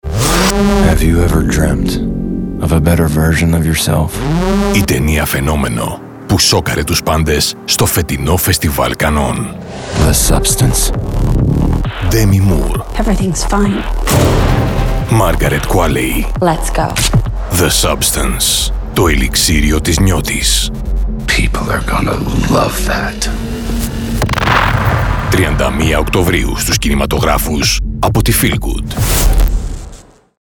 Hire Movie Trailer Voice Over Actors
Adult (30-50)
Movie trailer voice guys and movie trailer voice Over actors generally convey a sound that makes the audience sit up and take notice!